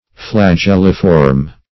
Search Result for " flagelliform" : The Collaborative International Dictionary of English v.0.48: Flagelliform \Fla*gel"li*form\, a. [L. flagellum a whip + -form.] Shaped like a whiplash; long, slender, round, flexible, and (comming) tapering.